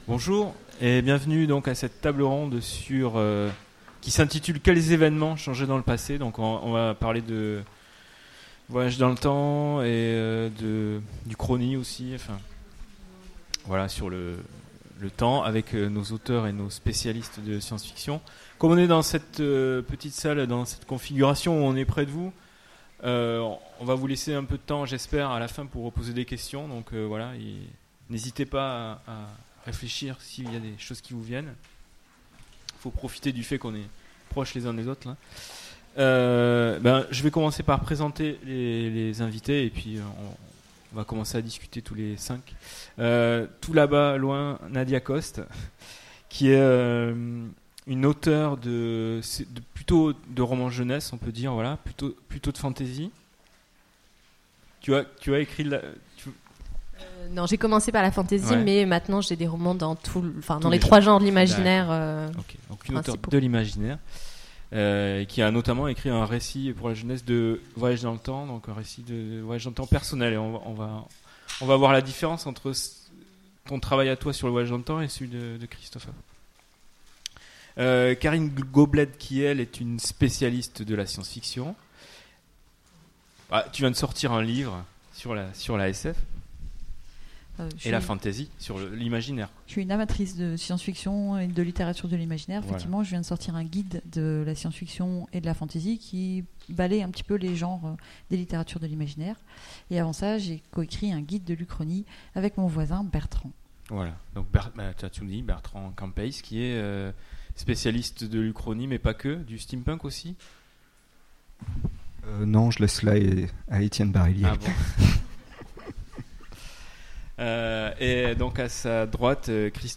Utopiales 2017 : Conférence Quel(s) événement(s) changer dans le passé ?